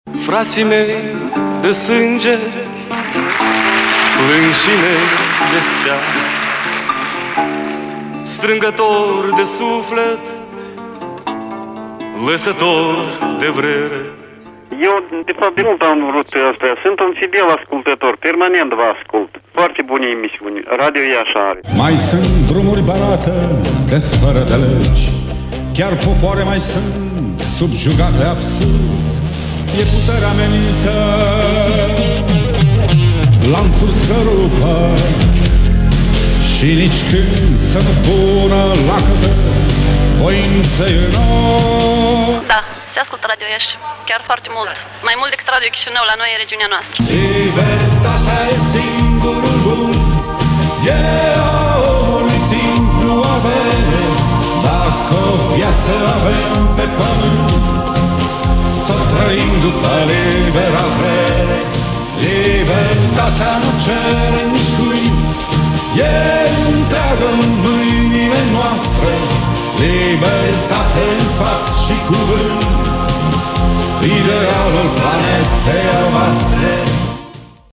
(Auto-promo)-interes basarabean pentru Radio Iași … 1’17’’